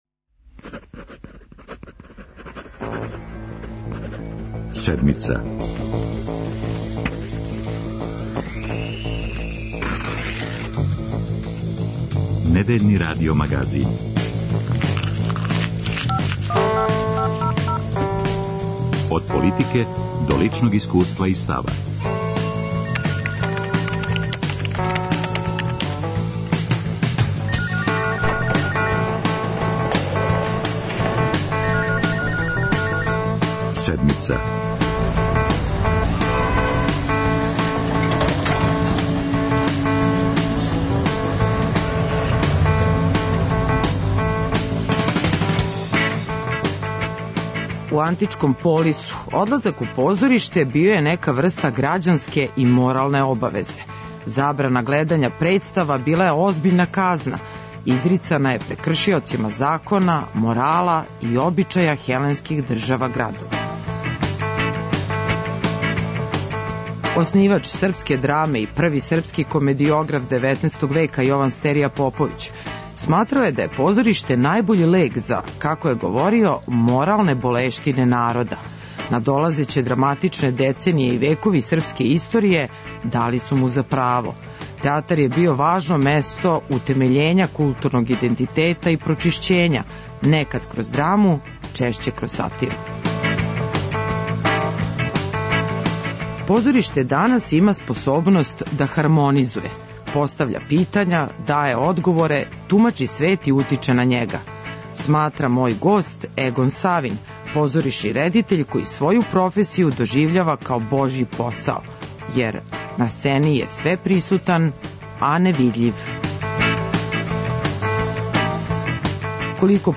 За Седмицу говори редитељ Егон Савин.